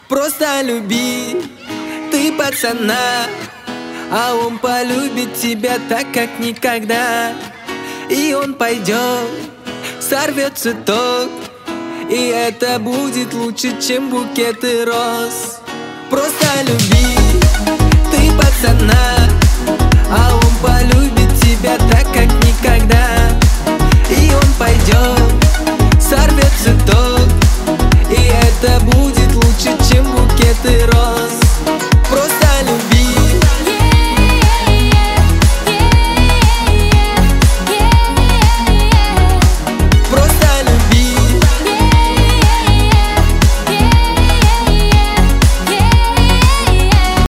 • Качество: 192, Stereo
поп